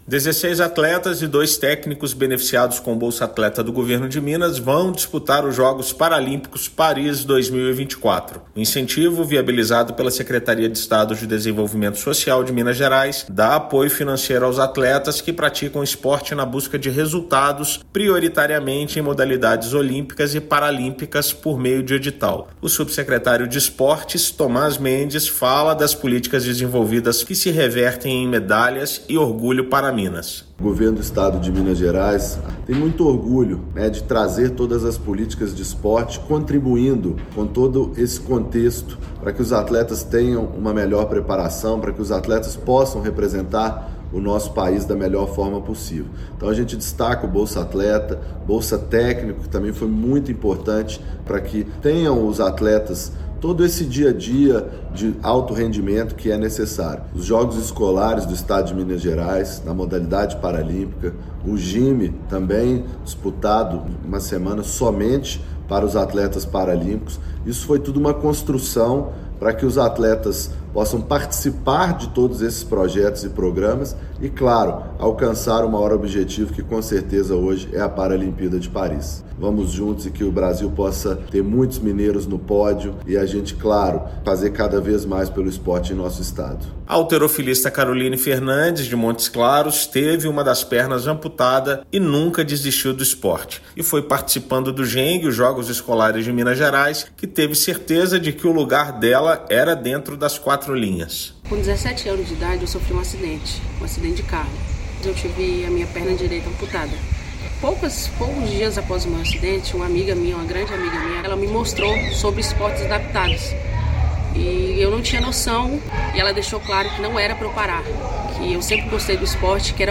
Dezesseis atletas e dois técnicos recebem o apoio do Governo de Minas para brigar por medalhas para o Brasil. Ouça matéria de rádio.